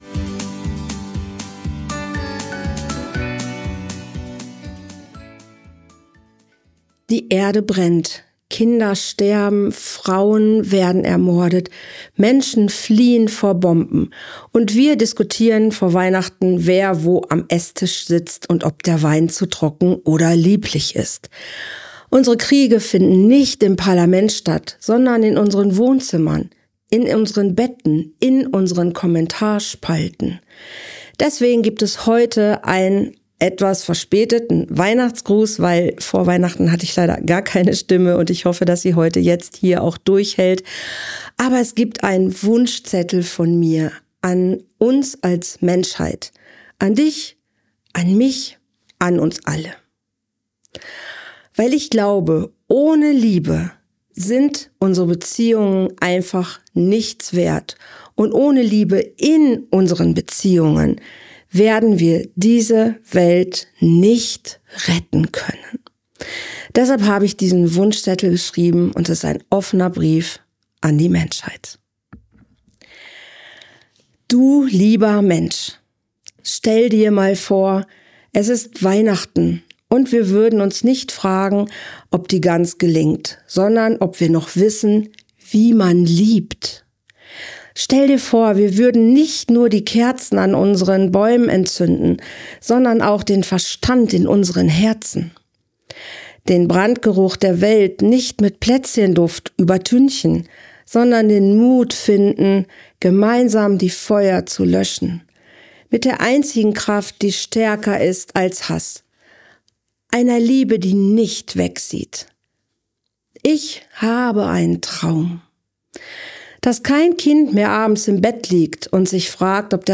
In dieser besonderen Weihnachtsfolge lese ich dir meinen Wunschzettel an die Menschheit vor. Kein kuscheliger Weihnachtsgruß, sondern ein offener Brief über Gewalt, Einsamkeit, Kindeswohlgefährdung, toxische Beziehungen, Krieg, Flucht, Tierwohl, Klima... und die eine Kraft, ohne die wir das alles nicht überleben: Liebe.